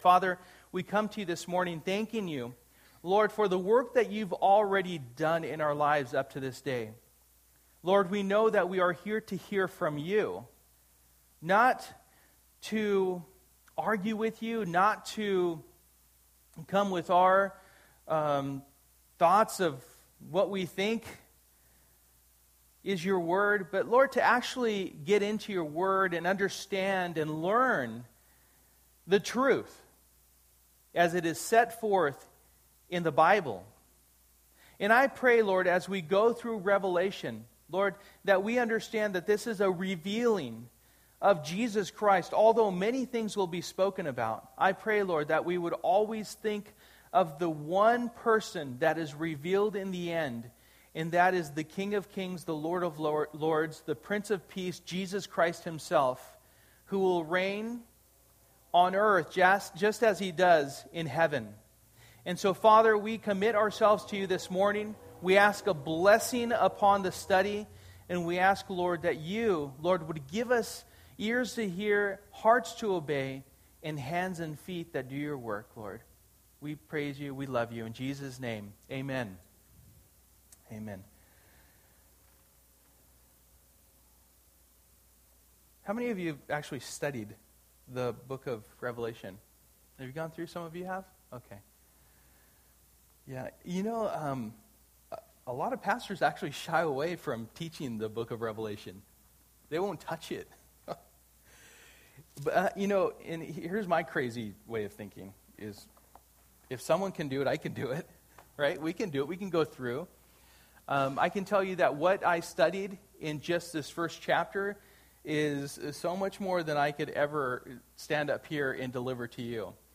Passage: Revelation 1:1-8 Service: Sunday Morning